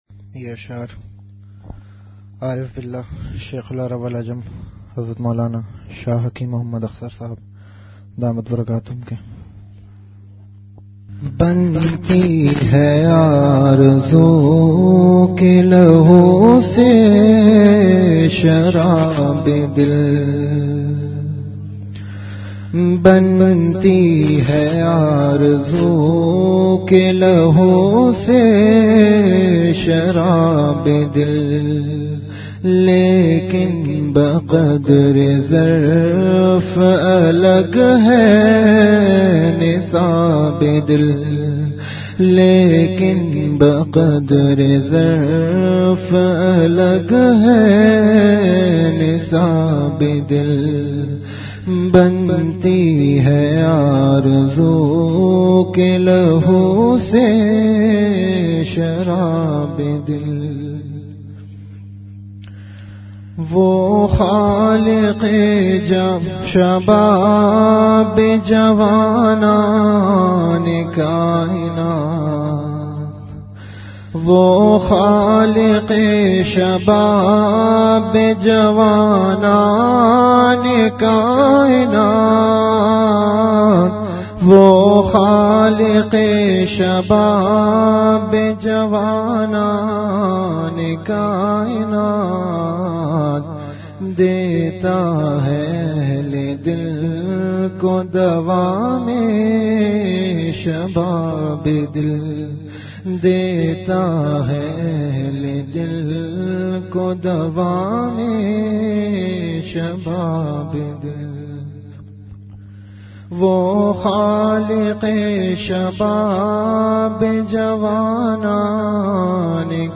Category Majlis-e-Zikr
Venue Home Event / Time After Isha Prayer